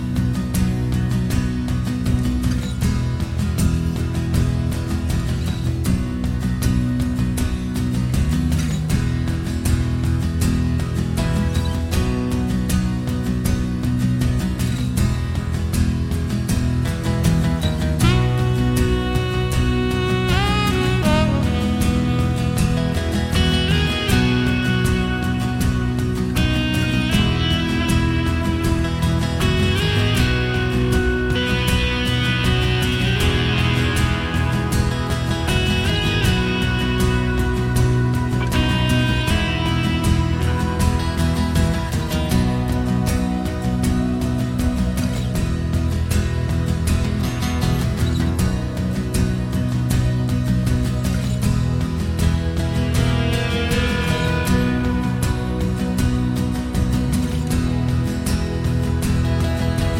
for solo singer Irish 4:06 Buy £1.50